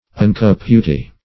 Meaning of ungka-puti. ungka-puti synonyms, pronunciation, spelling and more from Free Dictionary.
Search Result for " ungka-puti" : The Collaborative International Dictionary of English v.0.48: Ungka-puti \Ung"ka-pu`ti\, n. (Zool.)